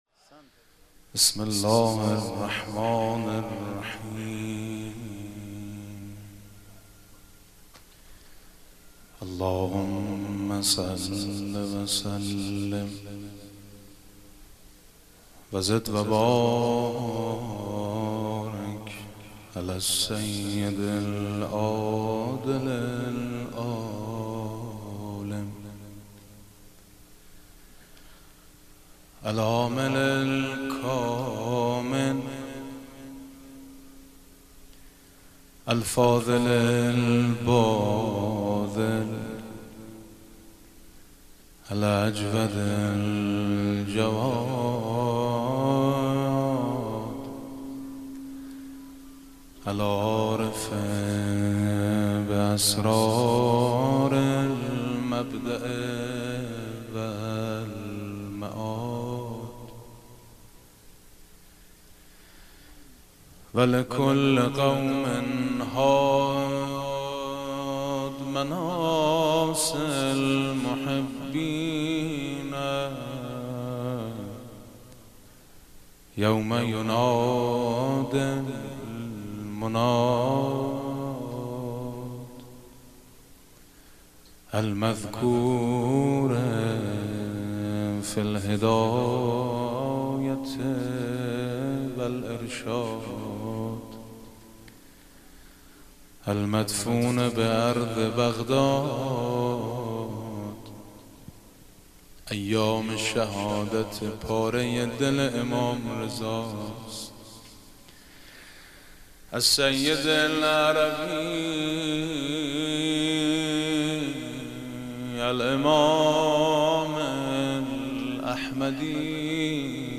السلام علیک یابن رئوف، السلام علیک یابن کریم | (مدینه منوره، هتل تهانی الزهرا
Hajj1394-HotelTahani.mp3